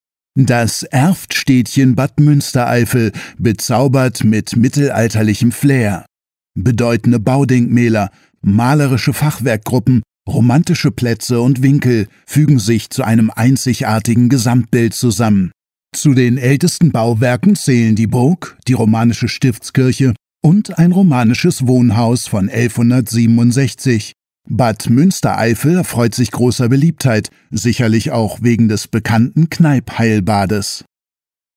Audiowiedergabe in einer Lightbox öffnen: Audioguide